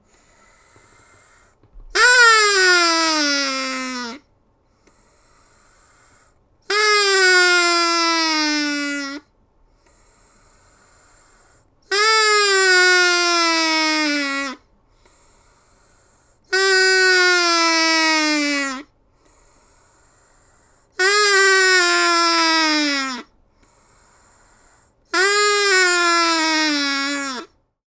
Blue-Snowball Blue-brand cartoon duck snoring sound effect free sound royalty free Movies & TV